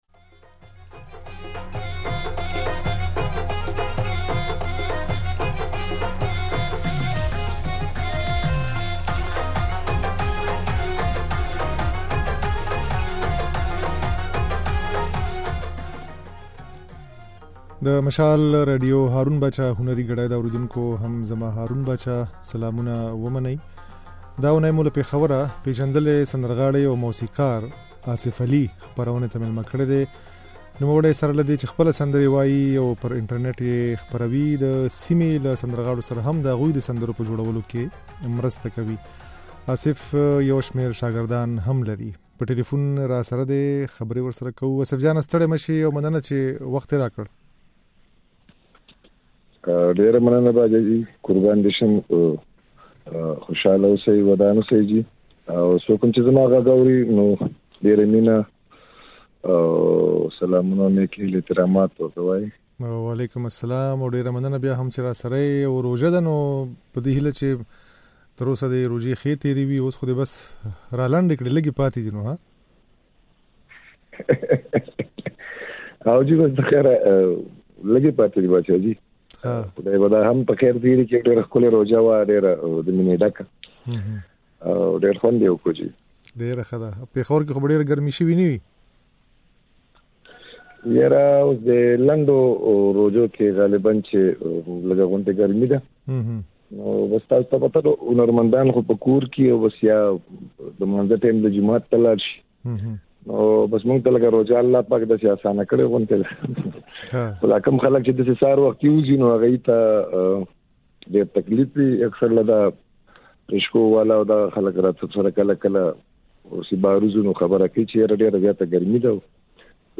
د نوموړي دا خبرې او ځينې سندرې يې د غږ په ځای کې اورېدای شئ.